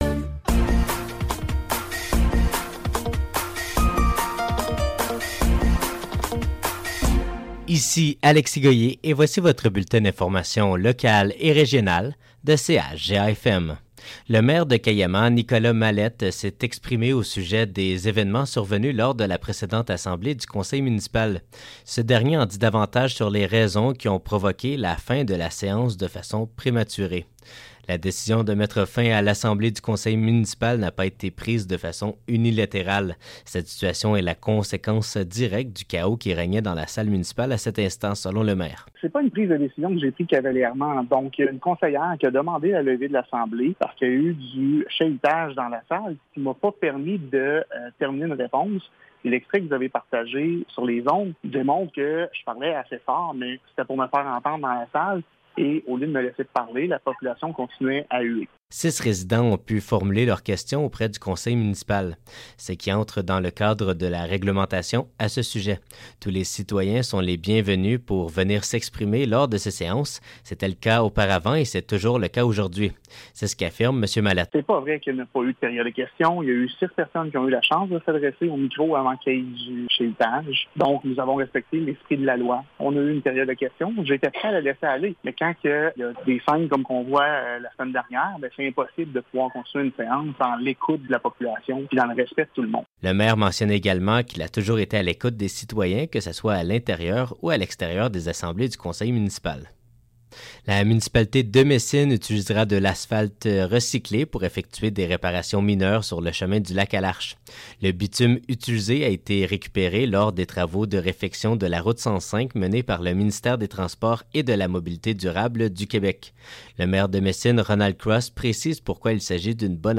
Nouvelles locales - 17 juillet 2024 - 10 h